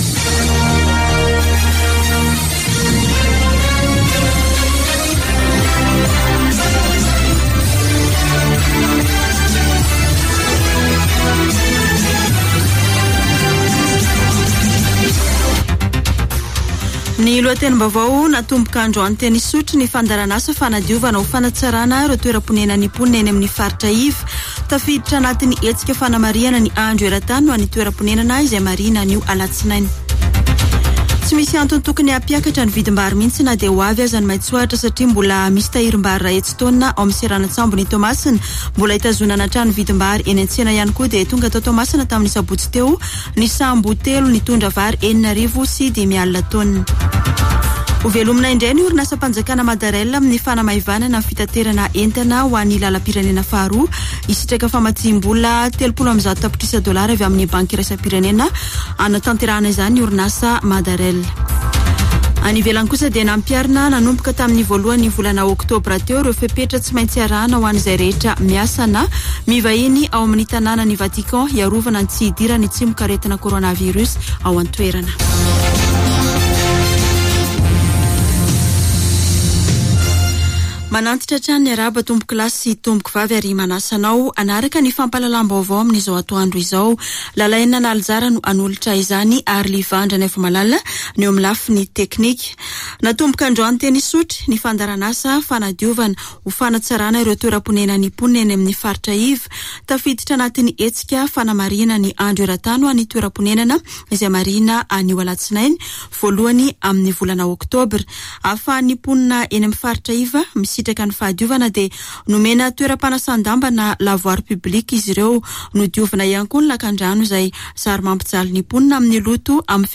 [Vaovao antoandro] Alatsinainy 04 oktobra 2021